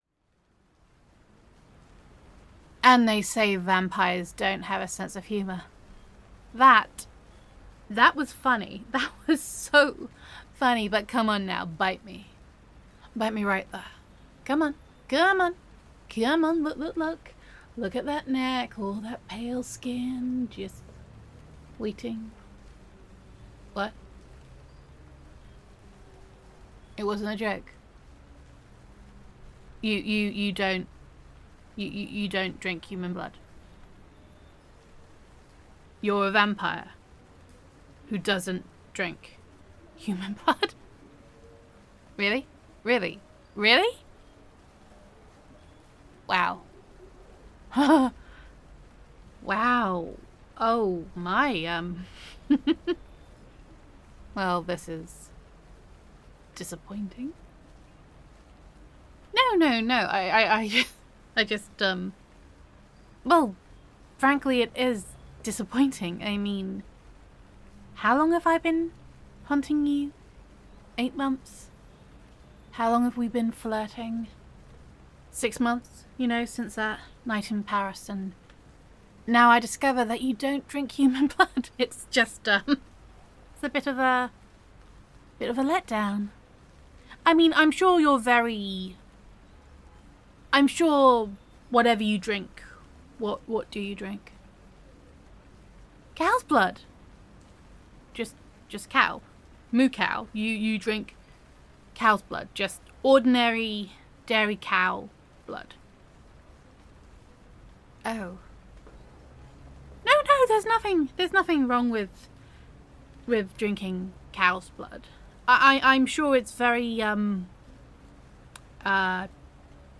[F4A] Moo Cow [Snobby Monster Hunter][Danger Kink][Not a Proper Vampire][Vampire Listener][Monster Hunter Roleplay][Cow Blood Drinking Vampire][Rainy Back Alley][Gender Neutral][After Finally Getting You Alone a Monster Hunter Suddenly Realises That You Do Not Drink Human Blood]